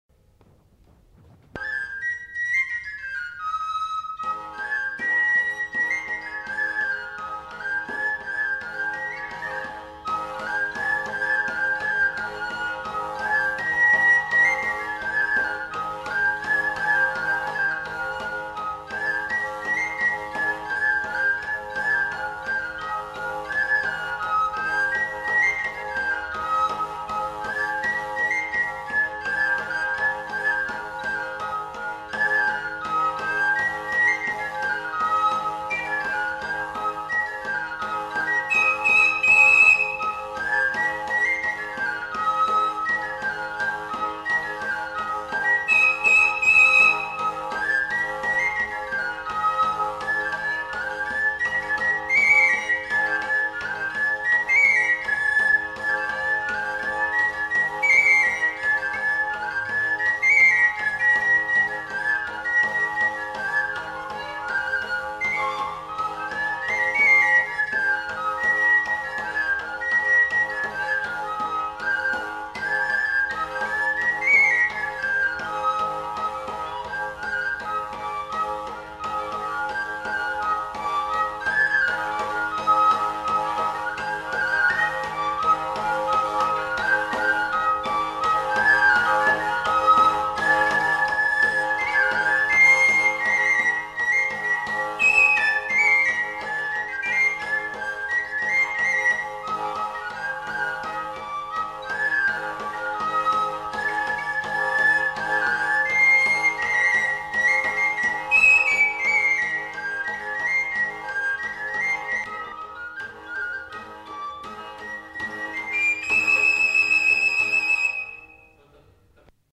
Aire culturelle : Béarn
Lieu : Bielle
Genre : morceau instrumental
Instrument de musique : flûte à trois trous ; tambourin à cordes
Danse : craba